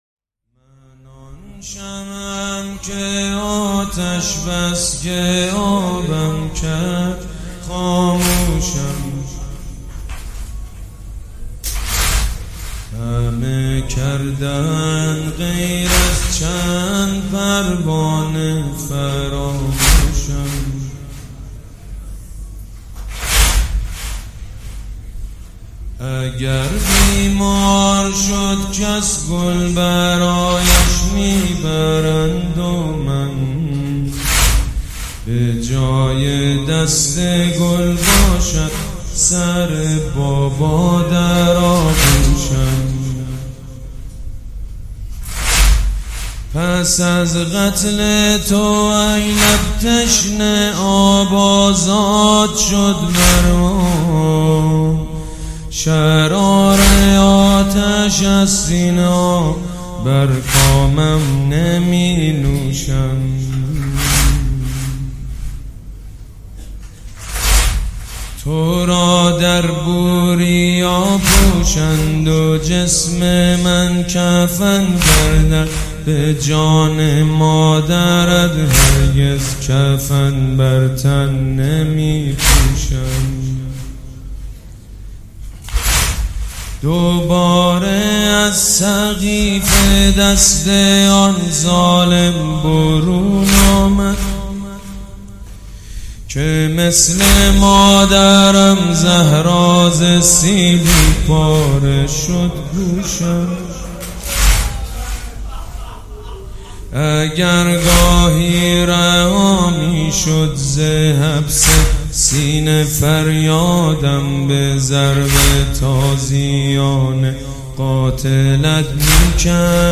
مداحی جدید حاج سید مجید بنی فاطمه حسینیه ی ریحانه الحسین شب سوم محرم97